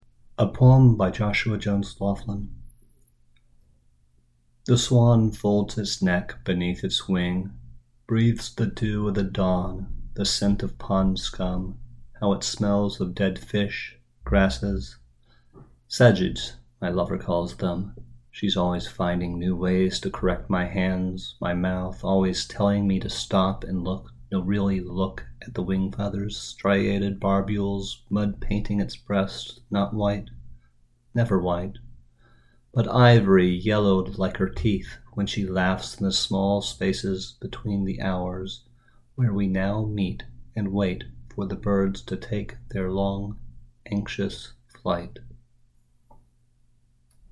Following along while listening to it read aloud simultaneously stimulates my eyes, my ears, and my imagination.